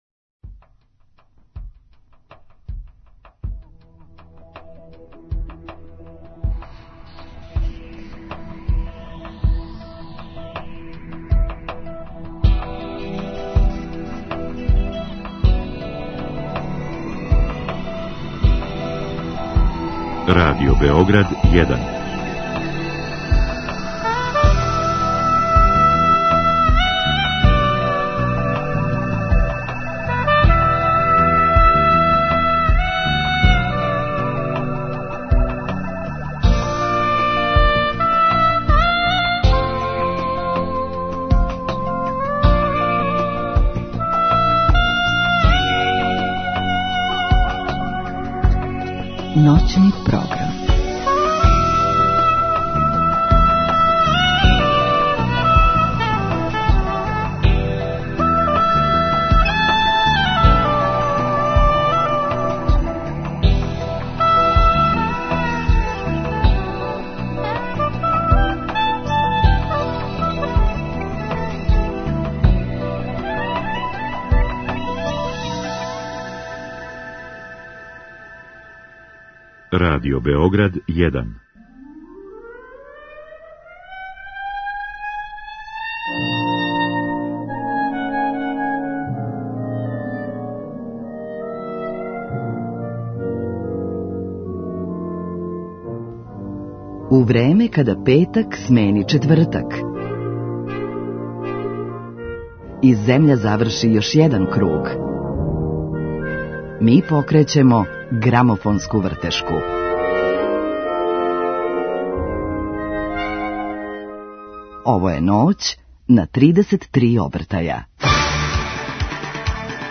Слушаћемо плоче које се тичу празничних жеља и чуда којима се надамо. Други сат је резервисан за Билбордову листу за данашњи дан у години из прошлости, а за крај представљамо новитет на винилу и то свежу новогодишњу комплацију!